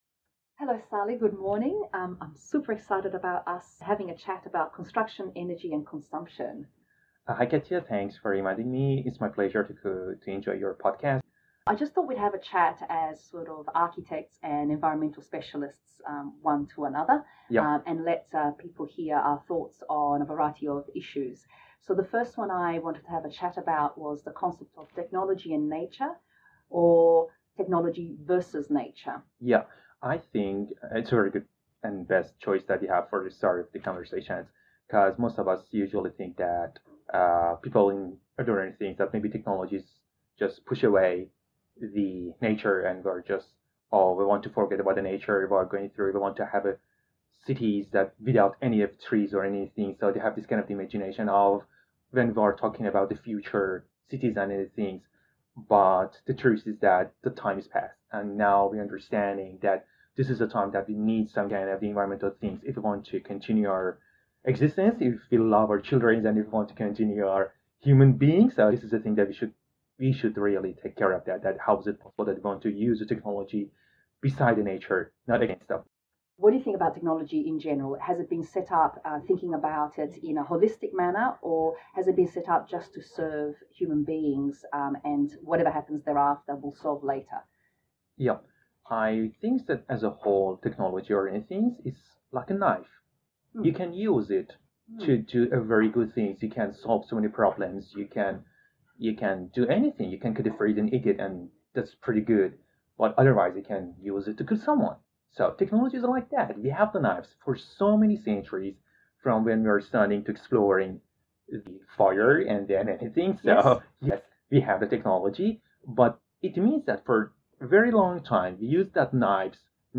I interview